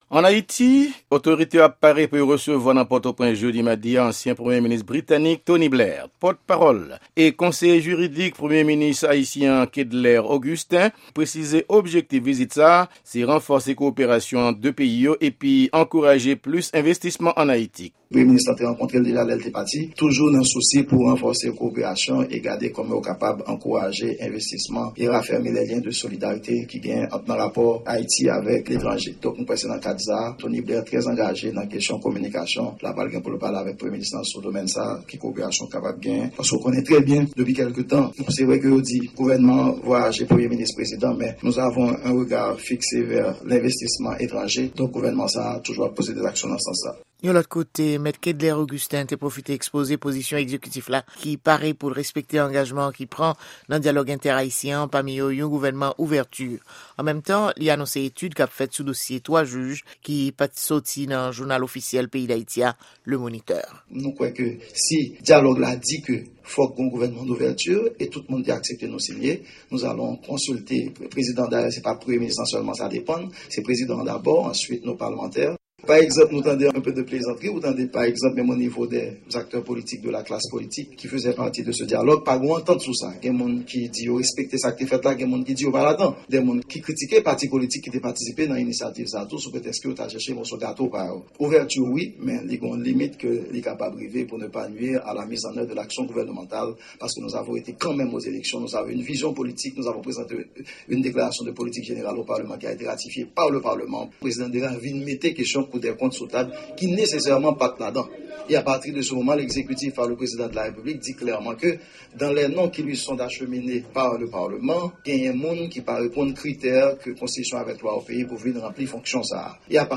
Repòtaj sou Vizit Tony Blair ann Ayiti madi 25 fevriye 2014 .